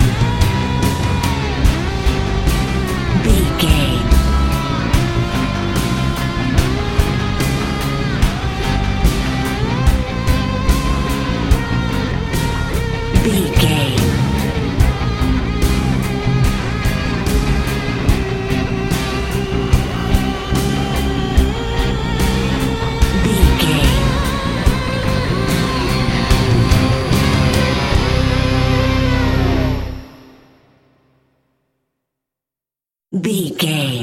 Music
In-crescendo
Thriller
Aeolian/Minor
synthesiser
ominous
dark
suspense
haunting
tense
creepy